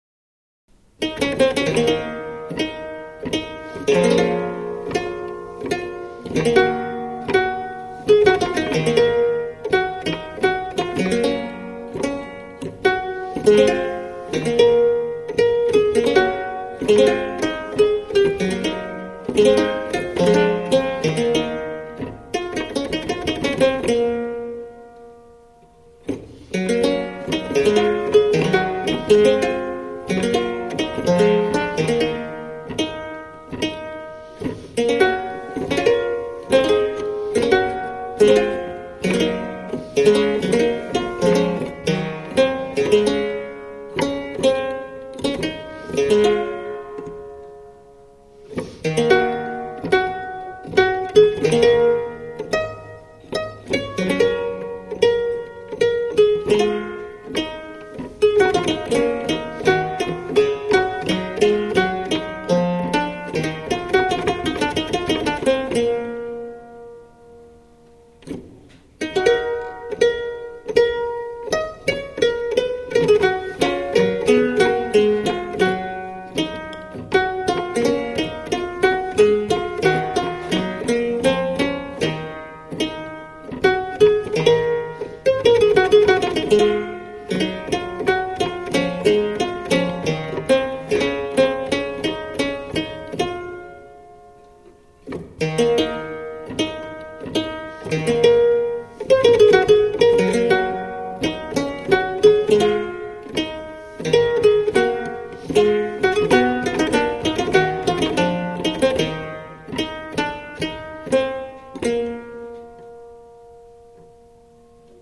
CLAVICORDO  e Monocordo di Pitagora
clavicordo2.mp3